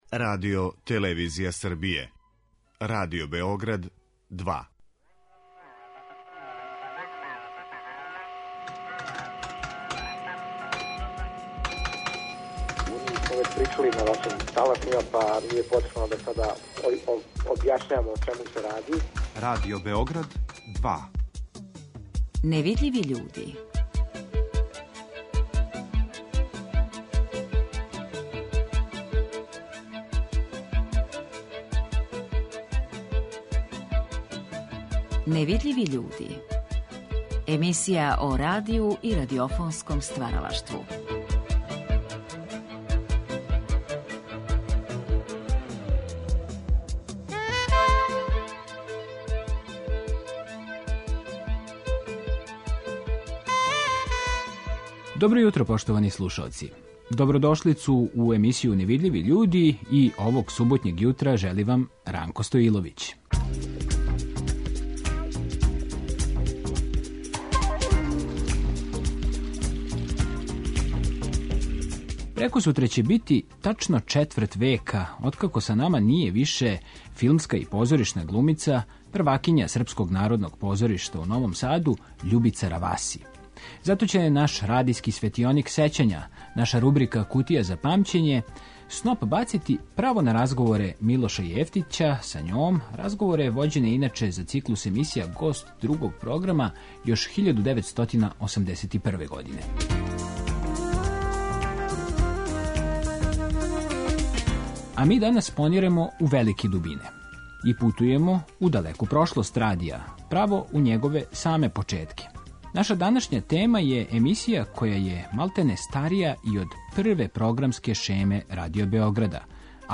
О њеном значају некада и данас разговарамо са историчарима радија, хидролозима, капетанима бродова, лађарима и риболовцима...
Емисија о радију и радиофонском стваралаштву.